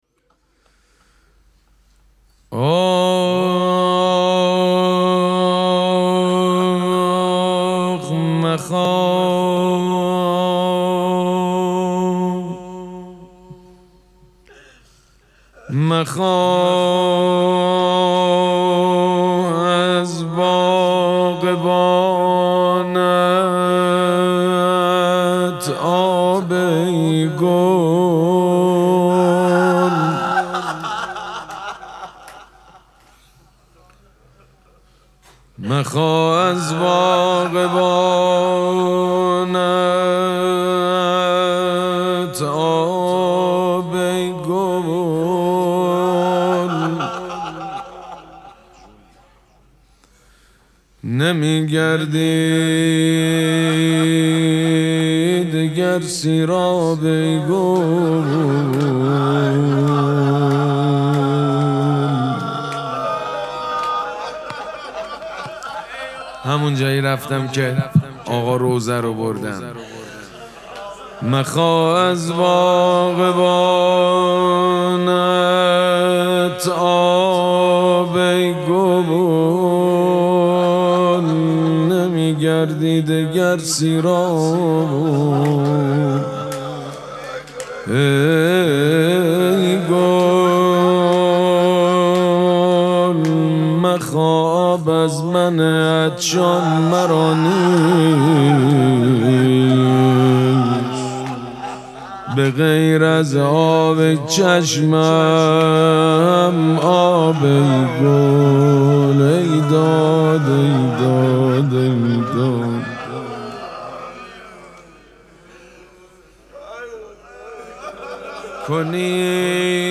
مراسم مناجات شب دوازدهم ماه مبارک رمضان چهارشنبه ۲۲ اسفند ماه ۱۴۰۳ | ۱۱ رمضان ۱۴۴۶ حسینیه ریحانه الحسین سلام الله علیها
سبک اثــر روضه مداح حاج سید مجید بنی فاطمه